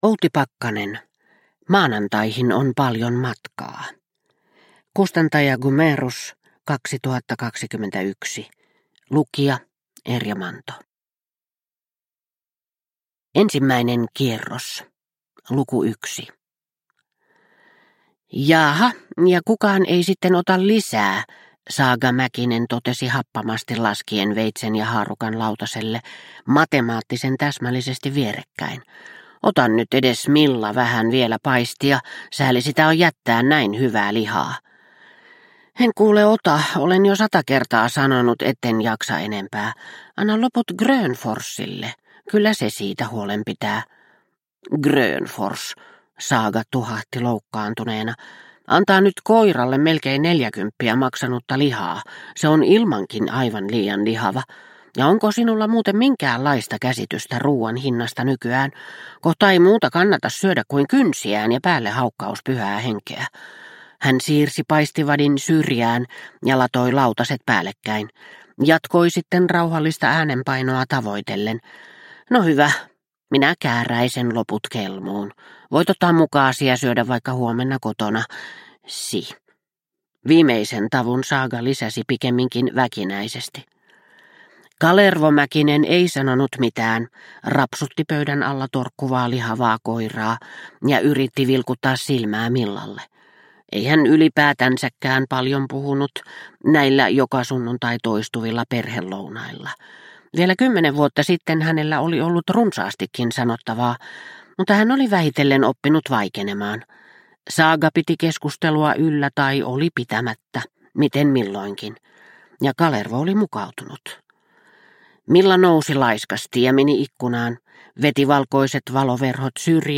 Maanantaihin on paljon matkaa – Ljudbok – Laddas ner